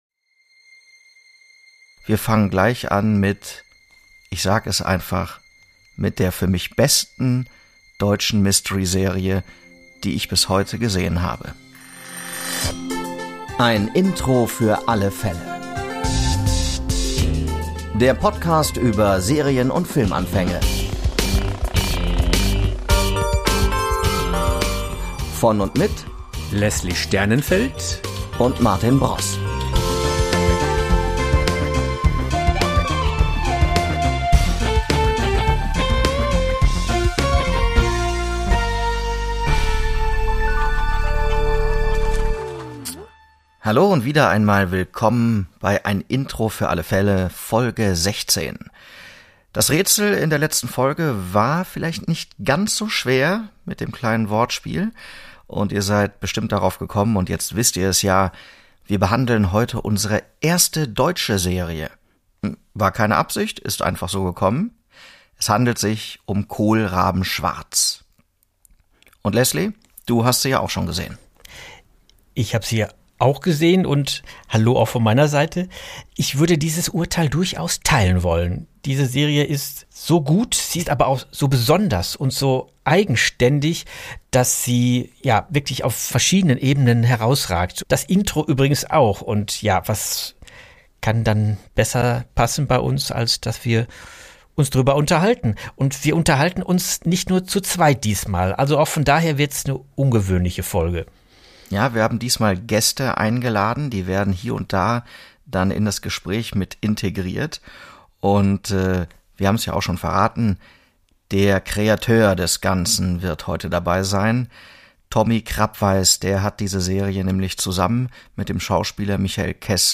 Kohlrabenschwarz – Doppelfolge Teil 1 inkl. Talk